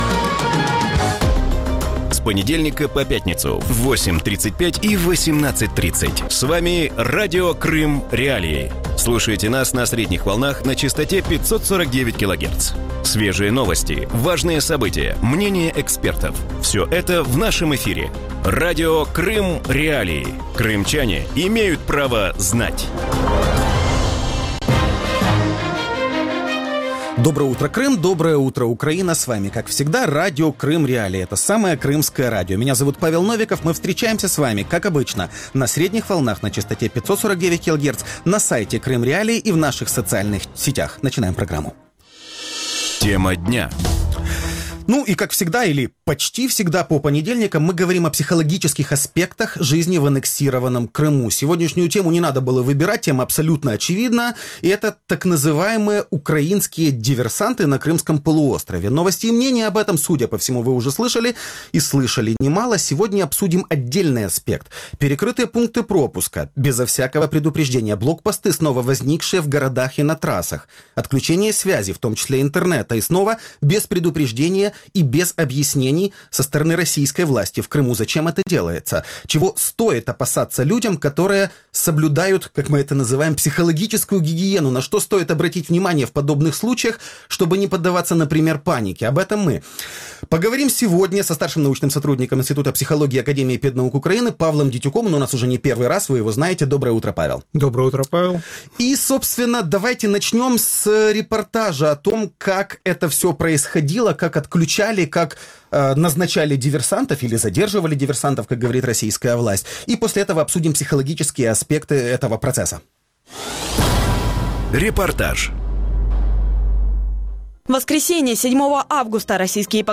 Вранці в ефірі Радіо Крим.Реалії говорять про те, як не втратити орієнтири в умовах інформаційного вакууму. Що робити, коли телевізор переповнений пропагандою, а навколо звучать жахливі чутки?